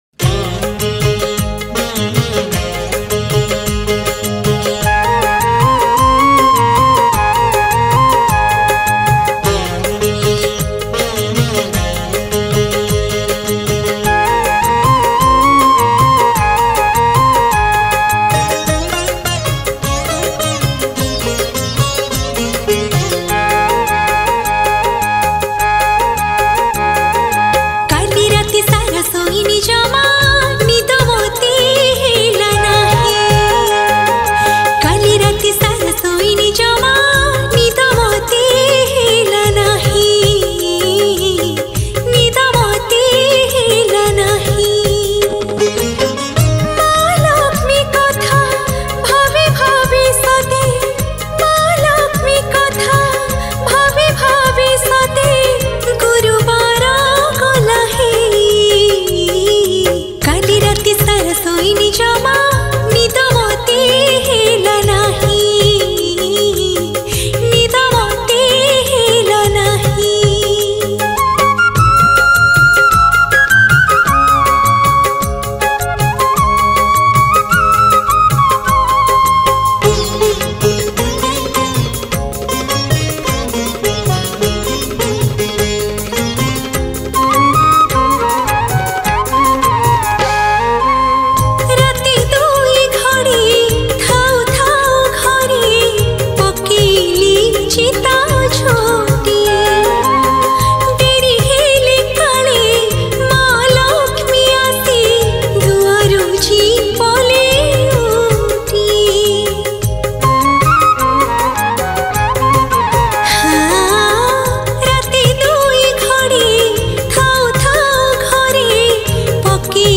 Manabasa Gurubara Bhajan